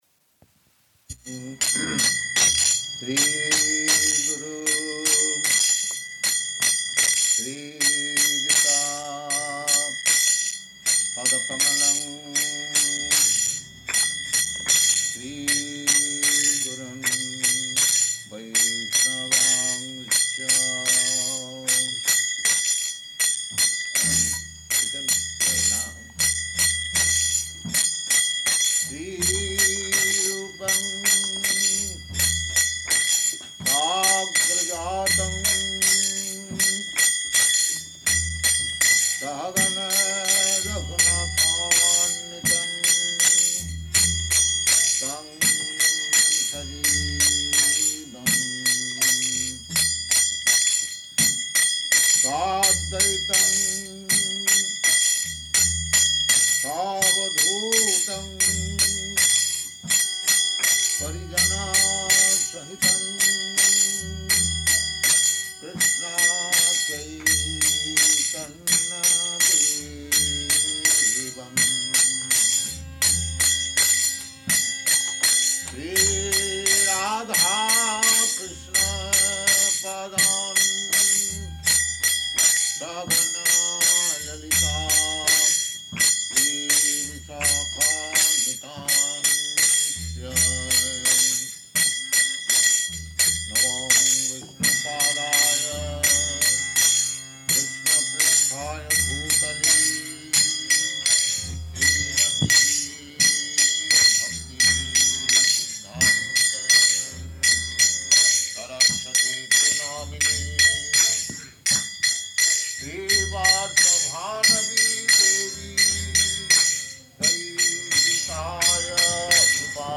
Lecture & Initiation